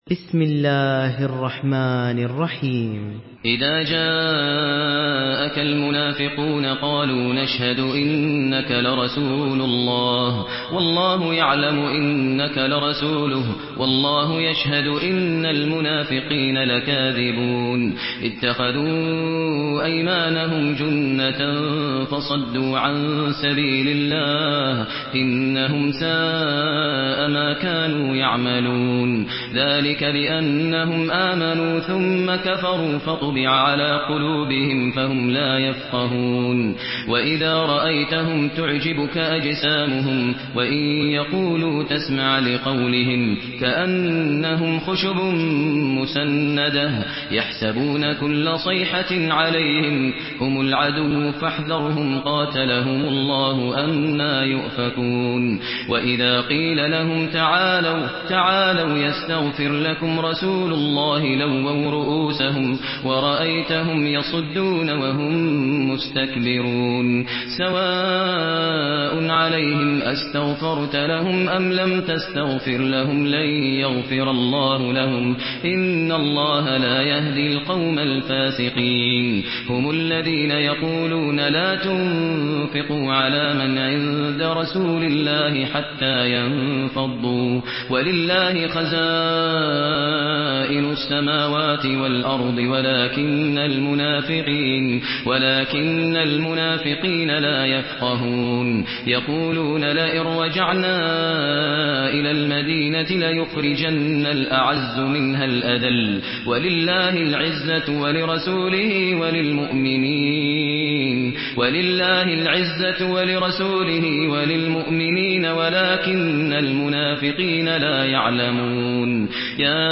Surah Al-Munafiqun MP3 in the Voice of Maher Al Muaiqly in Hafs Narration
Murattal